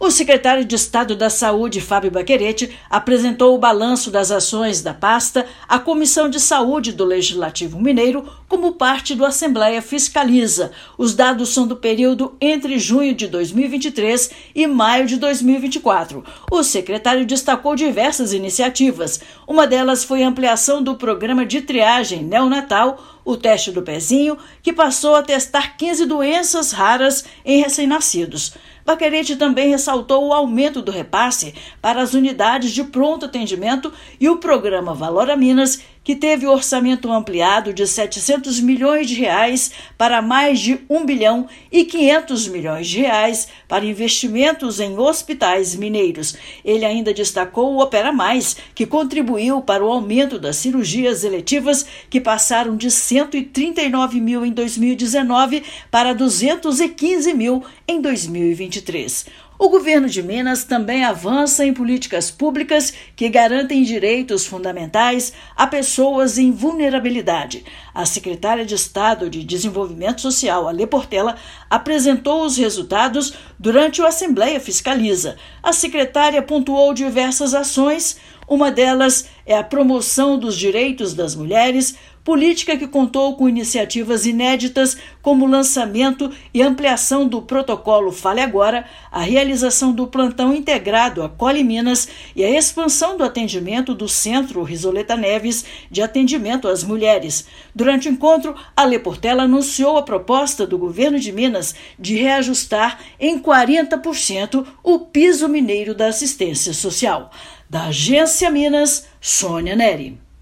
Os titulares das respectivas pastas apresentaram balanço das realizações. Ouça matéria de rádio.